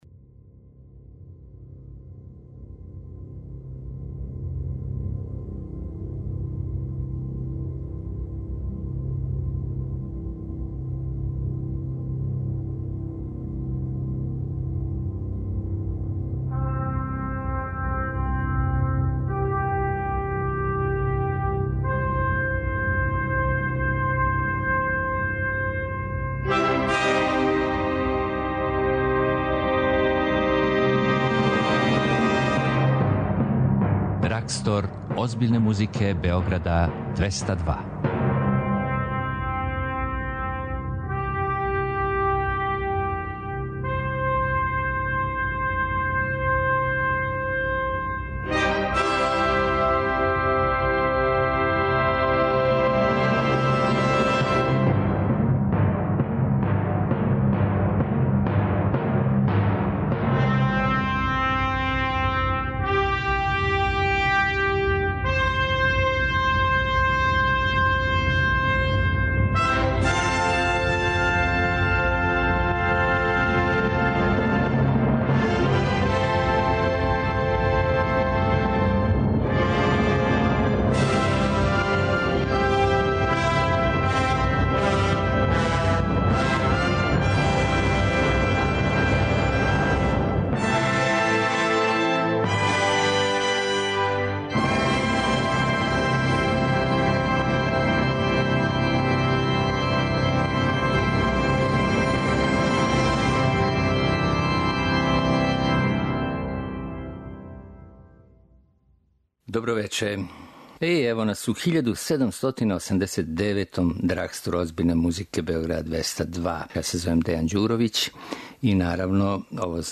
Култна емисија Београда 202 која промовише класичну музику.